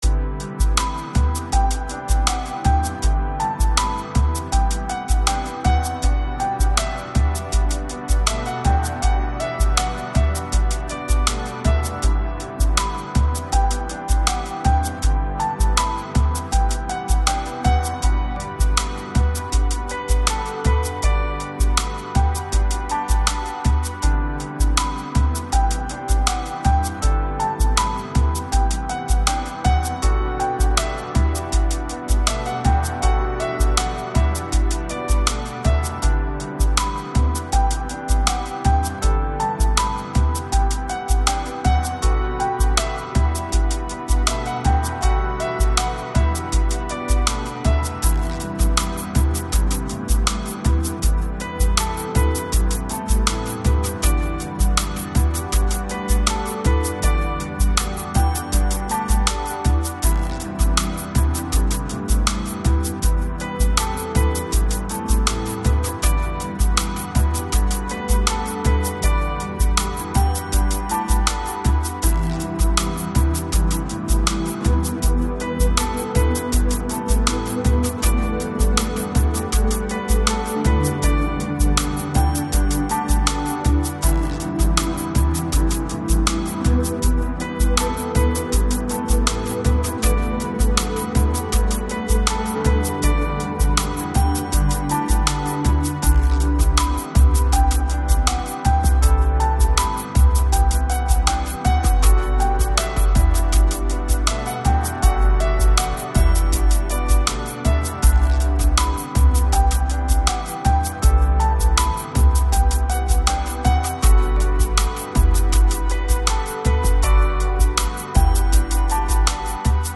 Lounge, Chill Out, Downtempo